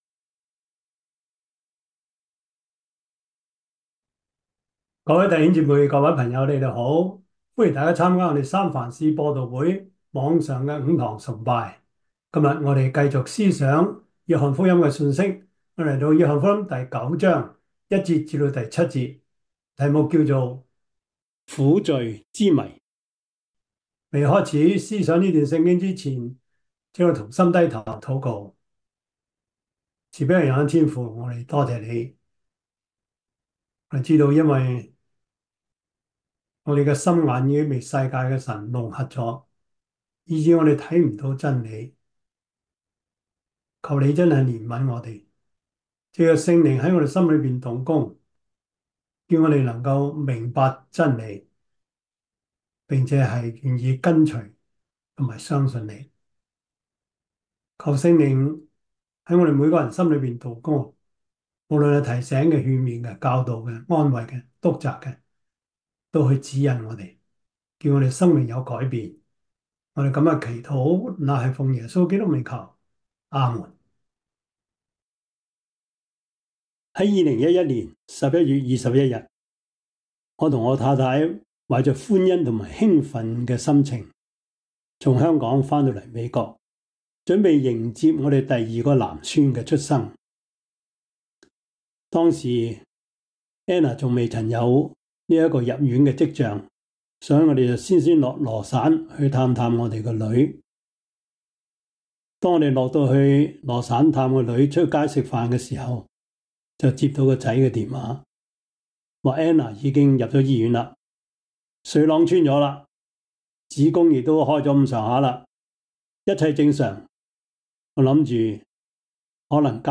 約翰福音 9:1-7 Service Type: 主日崇拜 約翰福音 9:1-7 Chinese Union Version